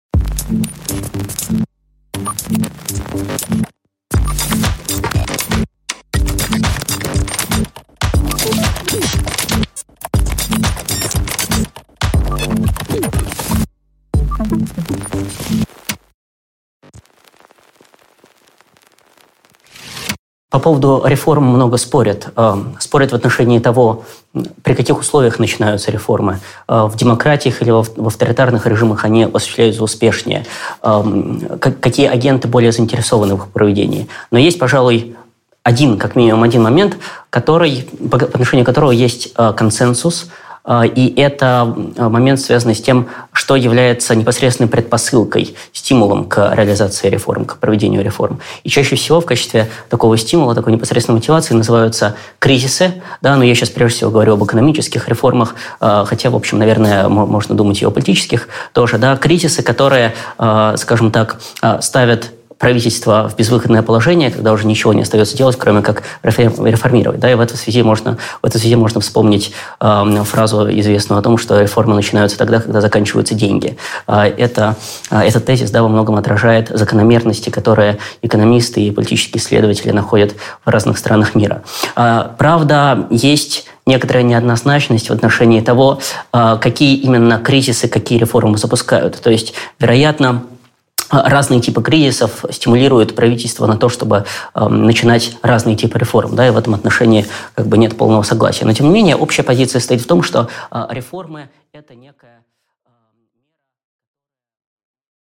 Аудиокнига Энергия перемен | Библиотека аудиокниг